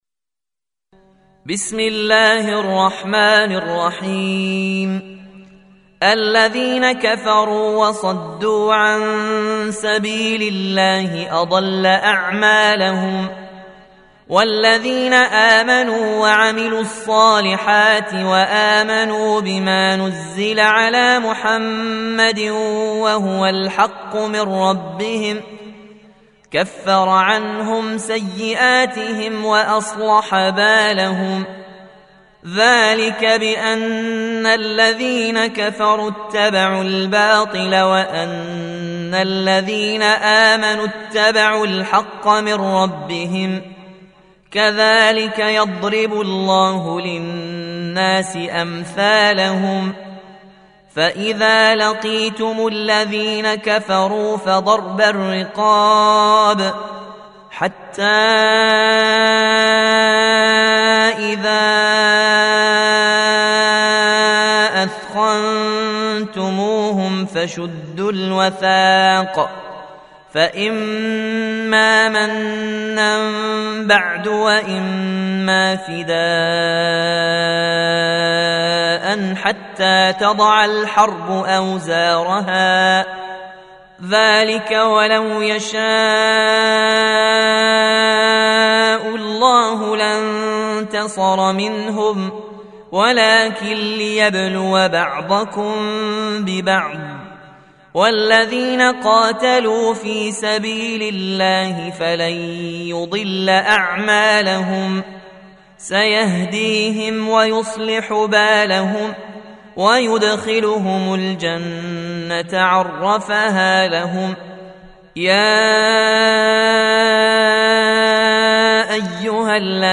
47. Surah Muhammad or Al-Qit�l سورة محمد Audio Quran Tarteel Recitation
Surah Sequence تتابع السورة Download Surah حمّل السورة Reciting Murattalah Audio for 47.